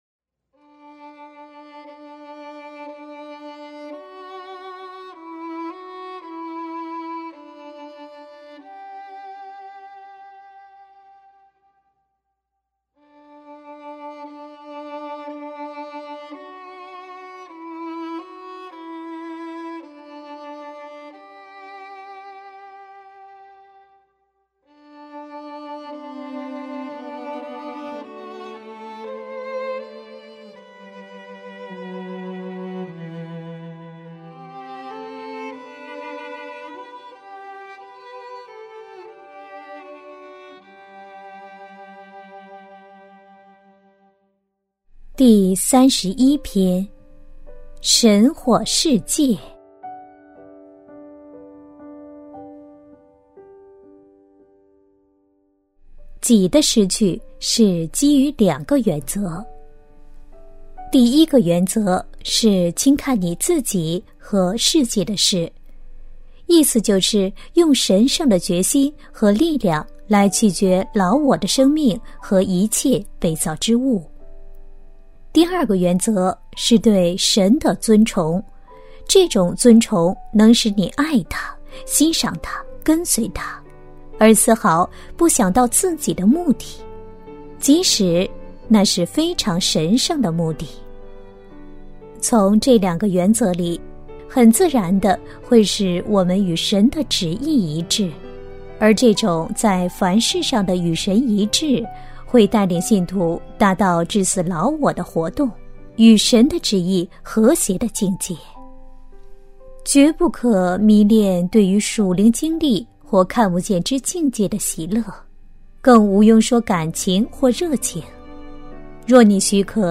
首页 > 有声书 | 灵性生活 | 灵程指引 > 灵程指引 第三十一篇：神或世界